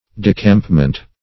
Decampment \De*camp"ment\, n. [Cf. F. d['e]campement.]